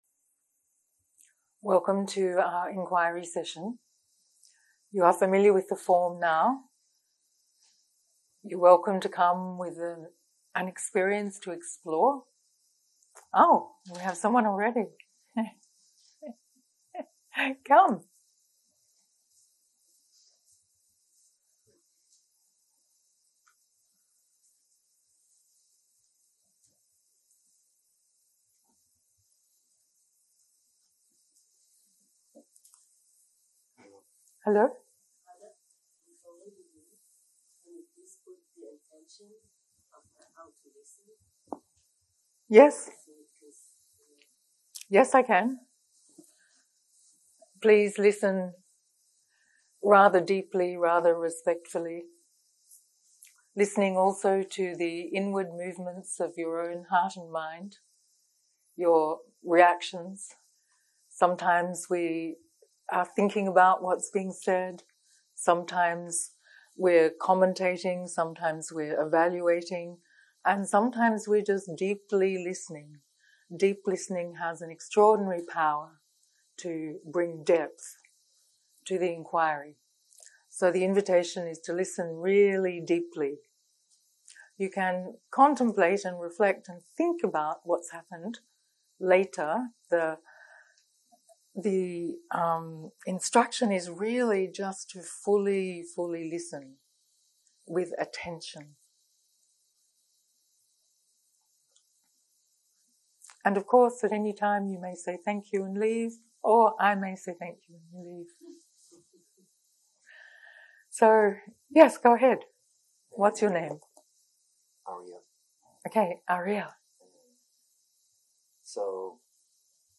סוג ההקלטה: חקירה